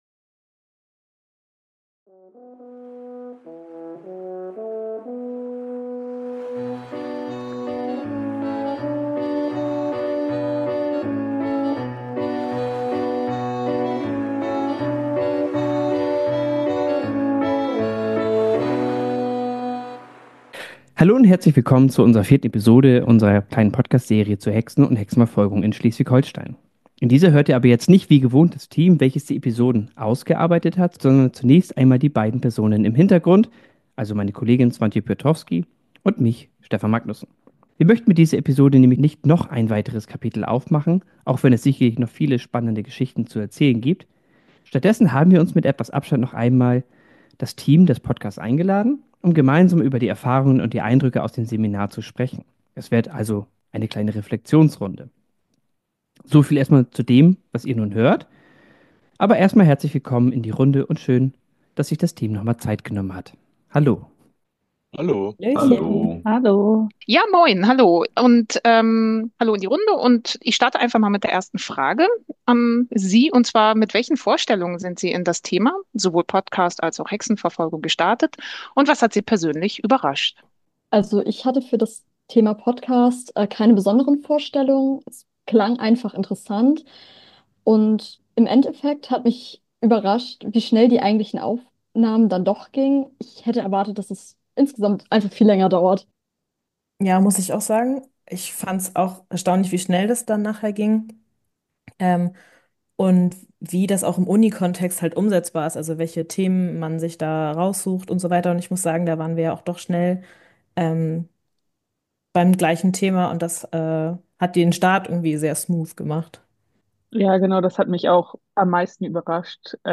In dieser kleinen Bonusepisode sprechen wir mit den Studierenden über die Erfahrung, erstmals einen Podcast erstellt zu haben